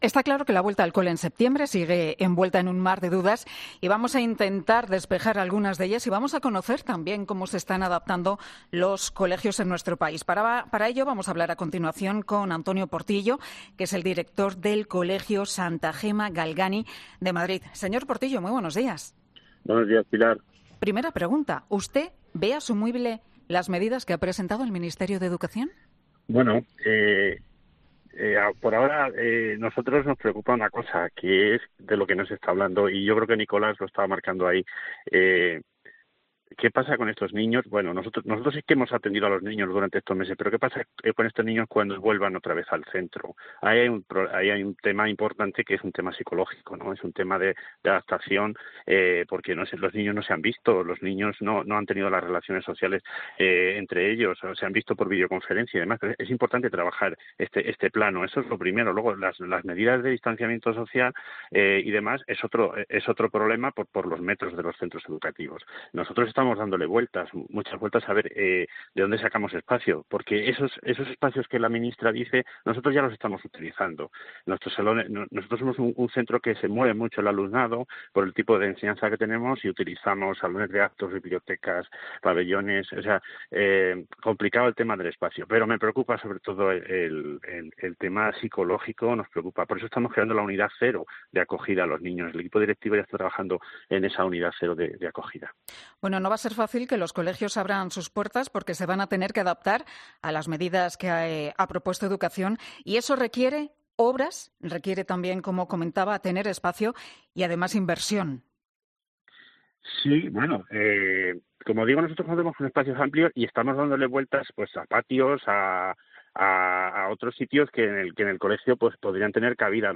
Tras ello, este viernes ha sido entrevistado en 'Herrera en COPE'